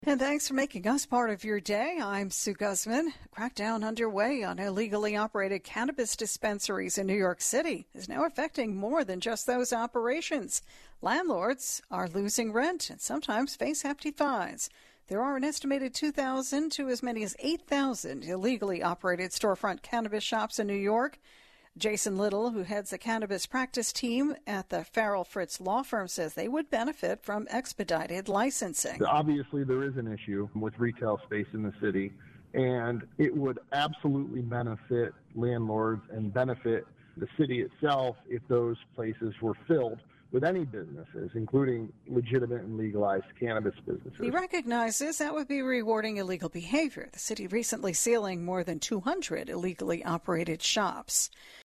was interviewed on Fox News Radio discussing New York’s crackdown on illegal cannabis businesses and its impact on real estate.